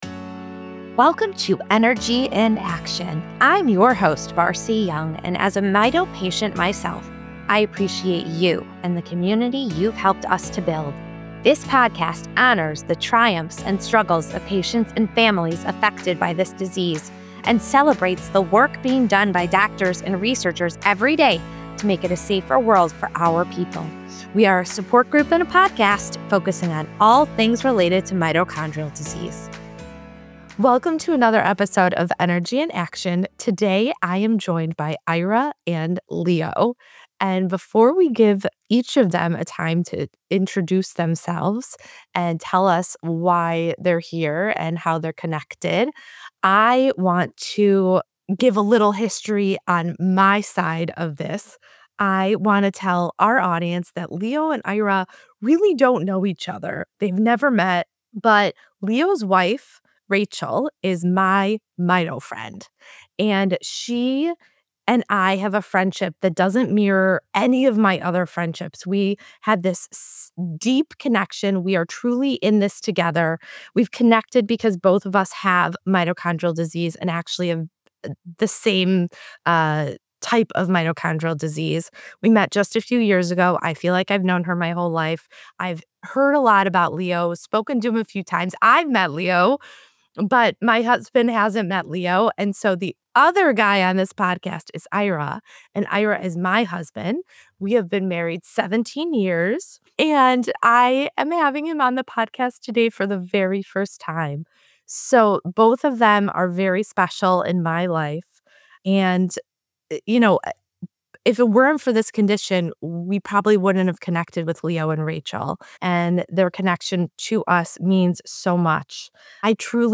In this candid conversation